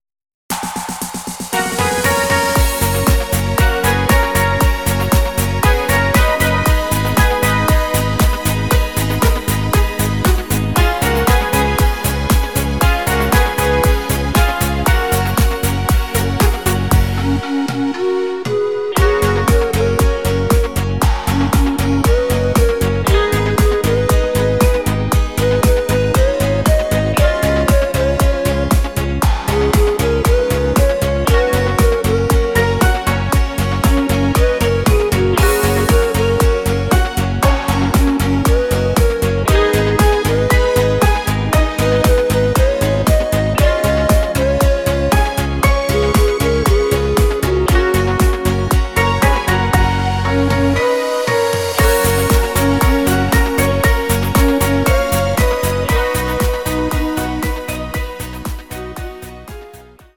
Audio Recordings based on Midi-files
Our Suggestions, Pop, German, 2020s